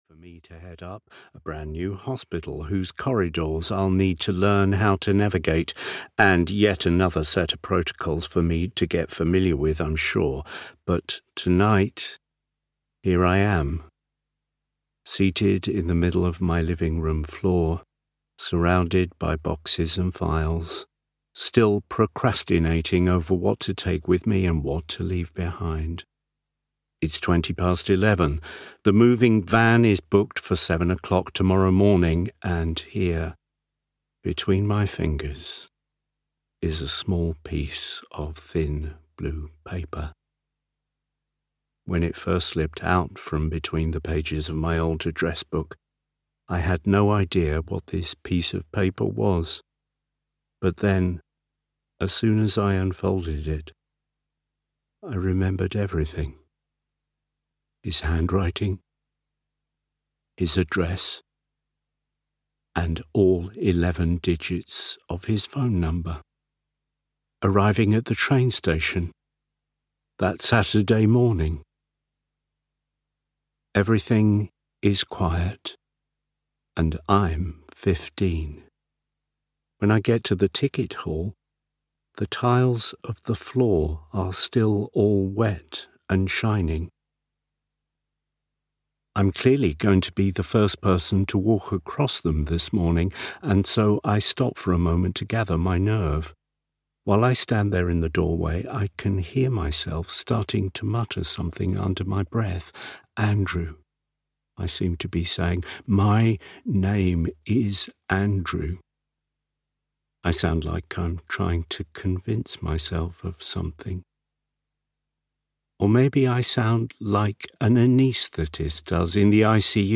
Address Book (EN) audiokniha
Ukázka z knihy
• InterpretNeil Bartlett